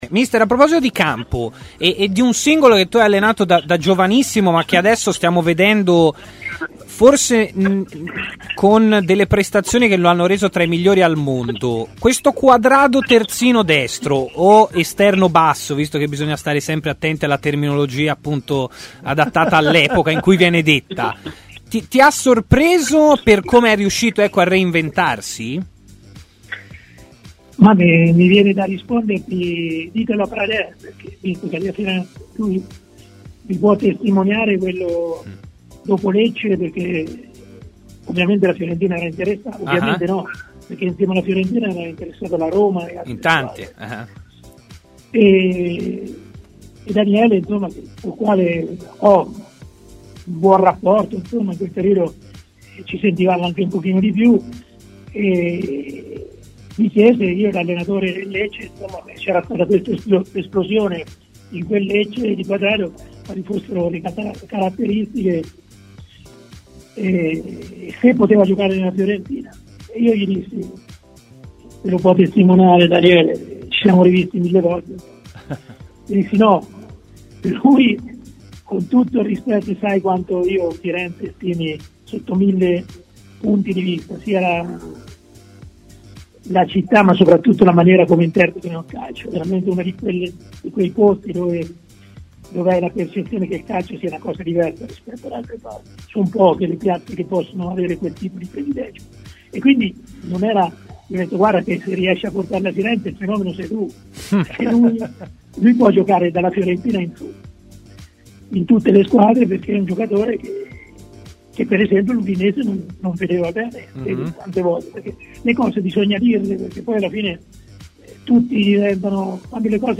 L'allenatore Serse Cosmi è intervenuto nel corso di Stadio Aperto, trasmissione di TMW Radio: "I verdetti della stagione passata, e mi riferisco sia alla A che alla B, in alcune situazioni avevano fatto intuire quanto tutto fosse condizionato da questo maledetto virus".